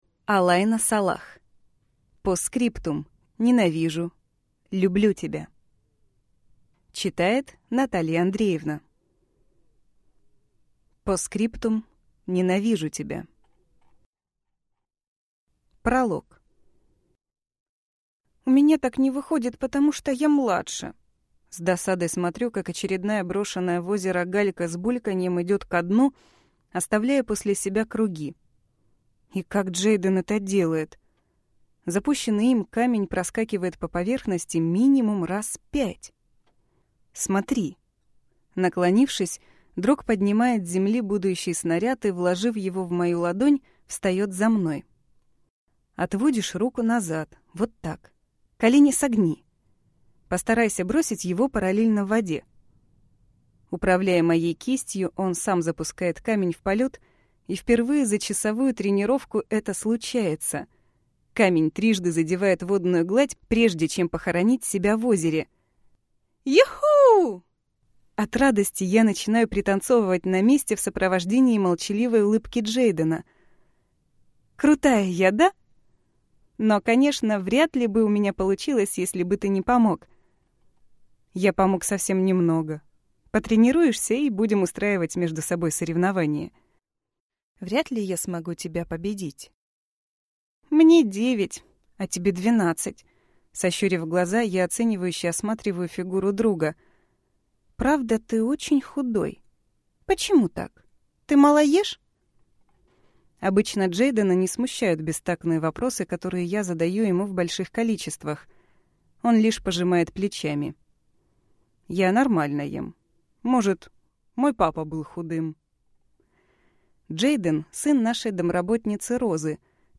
Аудиокнига Ненавижу/Люблю тебя | Библиотека аудиокниг